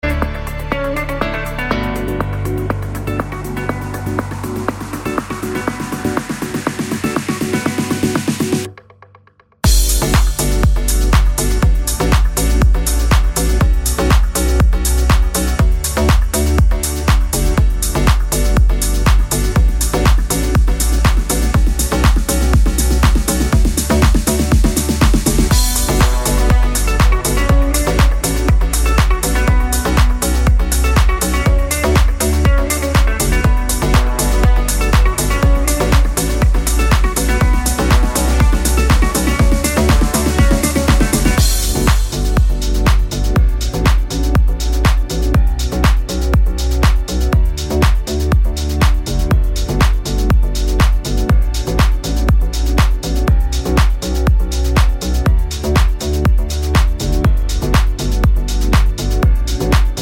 Middle Backing Vocals Only Pop (2020s) 3:21 Buy £1.50